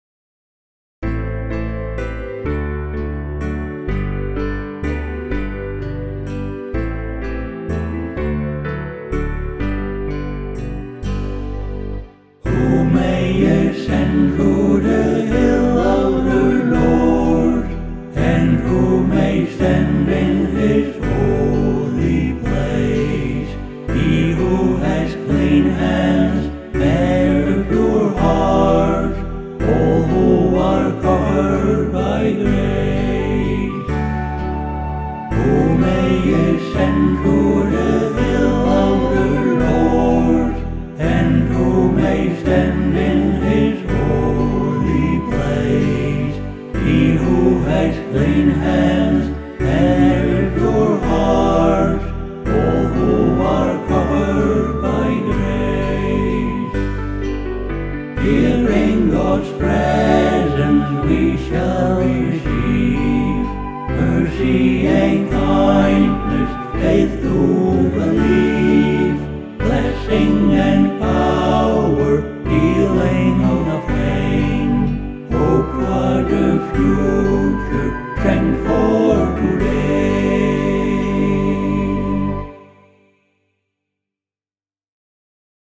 SATB
Based on Psalm 24:3,  the ascending and descending melodies